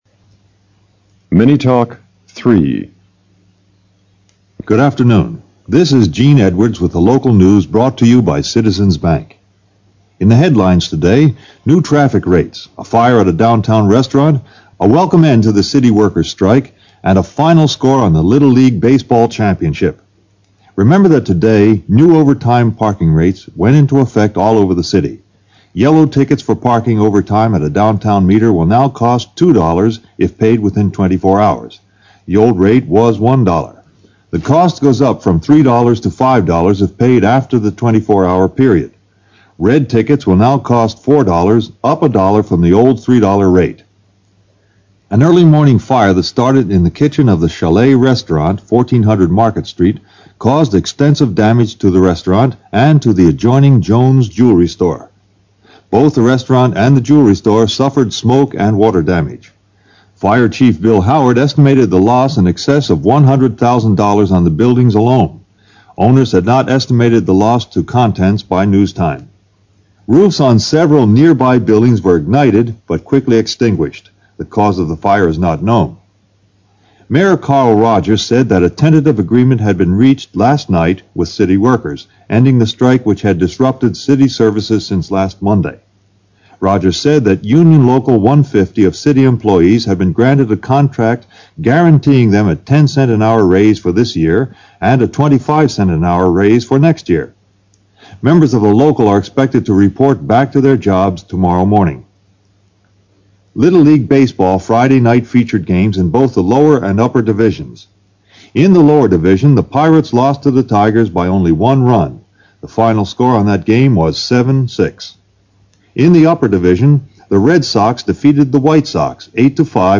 PART C: MINI TALKS
MINI TALK # 3